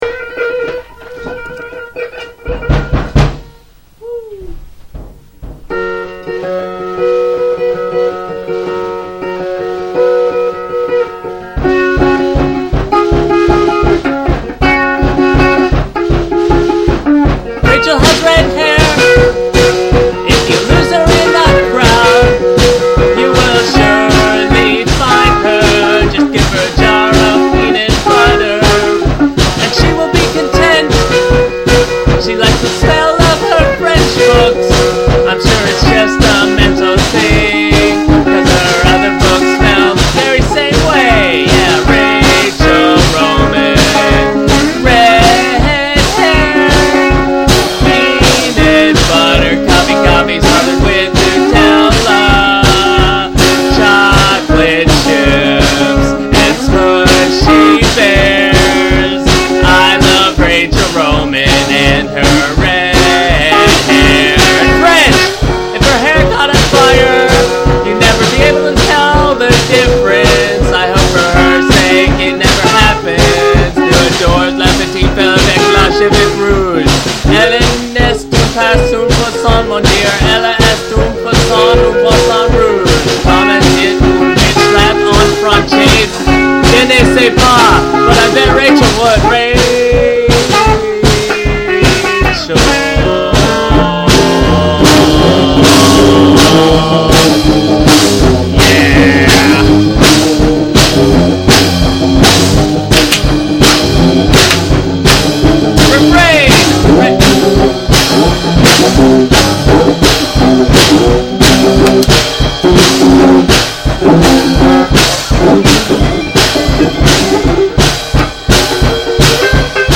a decent little album featuring a number of fourtrack songs.